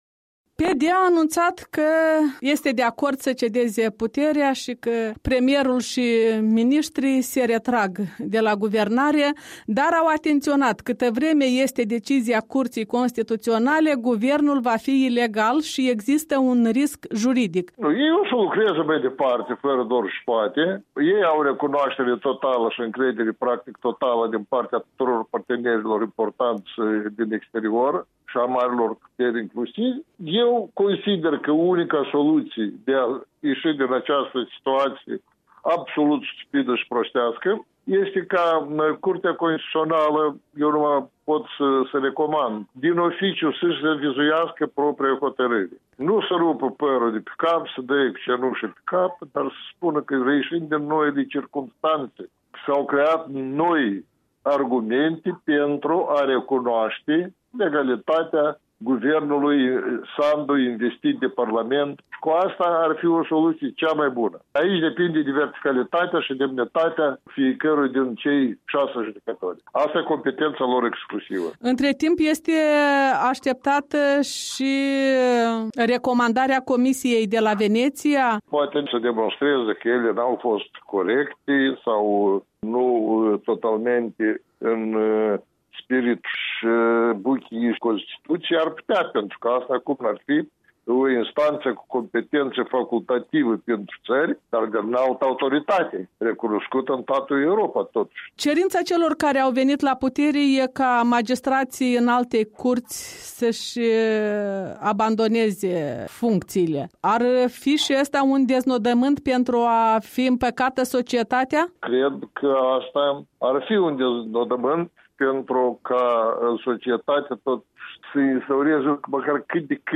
O discuție cu expertul în drept constituțional, fost judecător la CC.
Interviu cu expertul în drept constituțional Nicolae Osmochescu